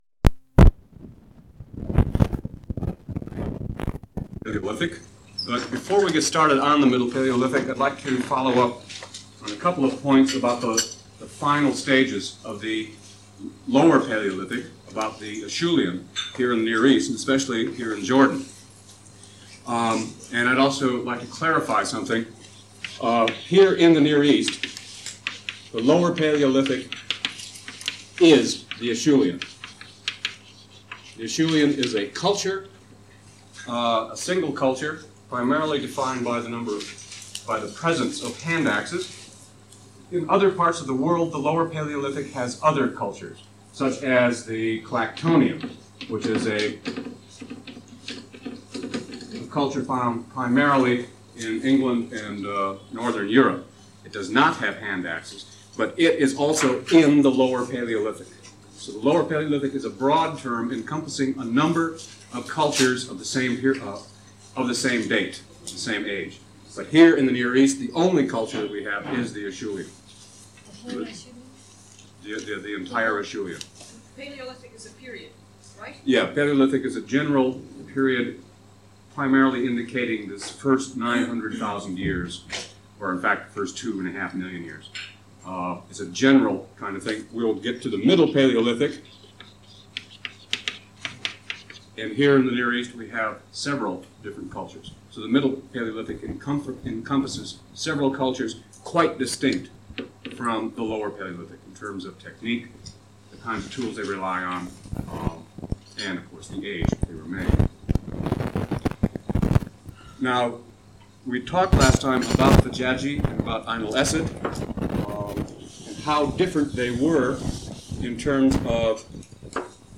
Stone Age of Jordan, Lecture 6: Middle Paleolithic, Upper Paleolithic
Format en audiocassette ID from Starchive 417942 Tag en Archaeology -- Methodology en Excavations (Archaeology) -- Jordan Item sets ACOR Audio-visual Collection Media Stone_Age_06_access.mp3